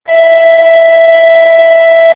e-tune
There is a difference of 2 octaves between the low E and the high e.
snaar1e.amr